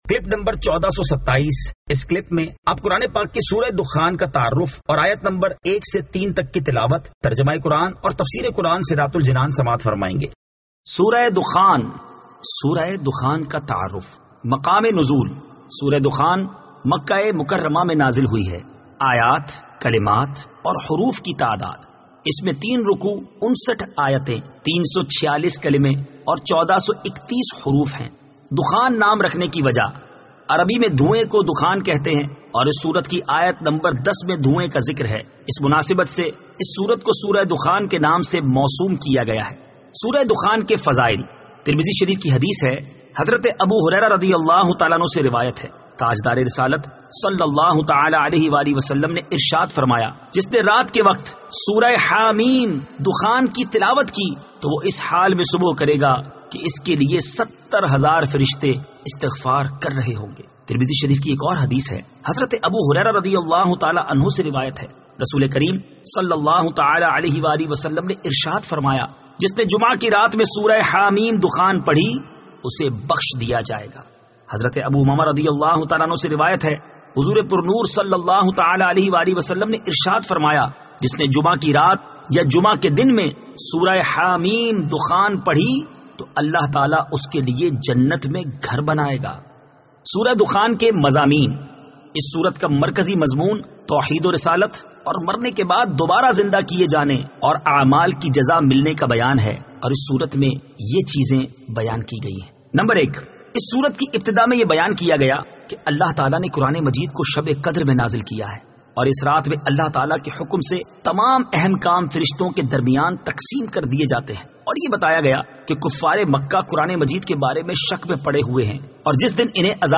Surah Ad-Dukhan 01 To 03 Tilawat , Tarjama , Tafseer